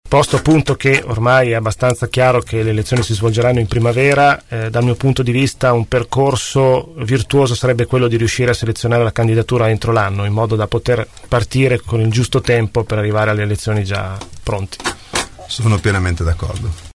ospiti dei nostri studi.